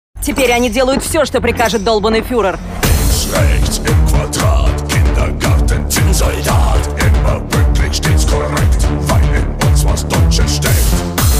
Немецкий марш